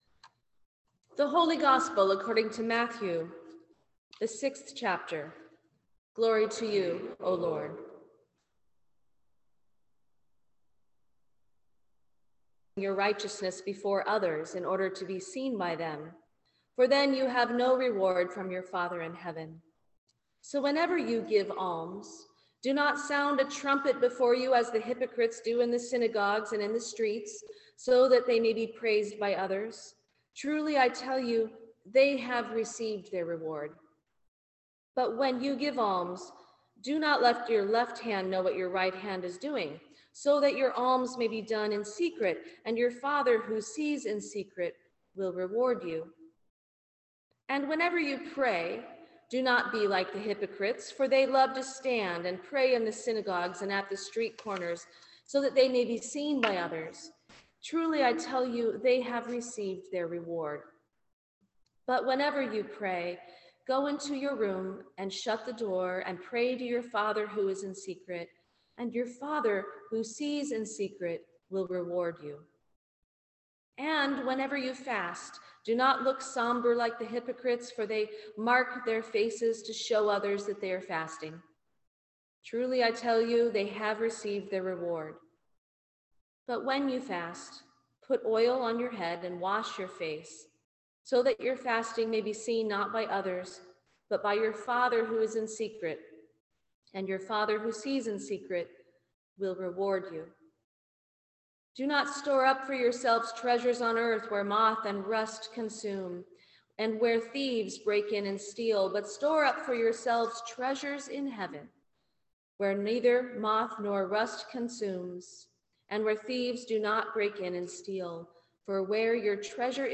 Sermon for Ash Wednesday 2023